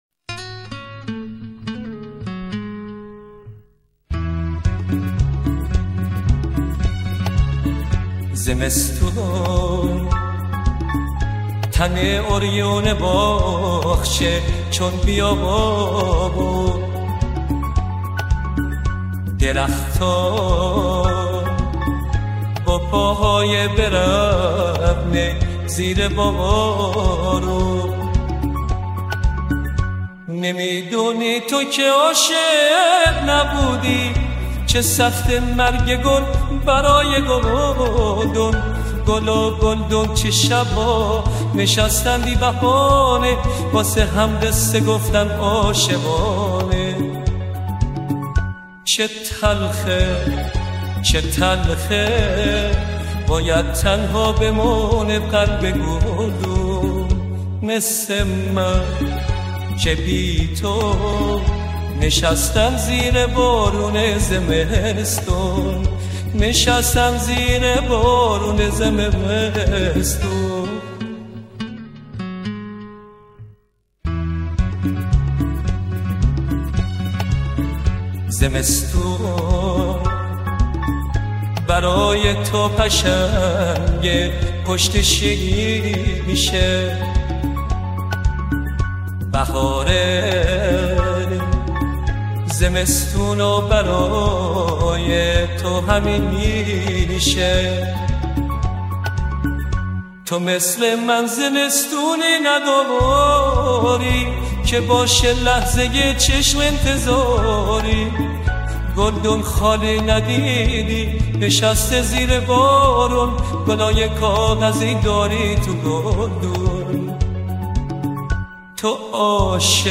پاپ ایرانی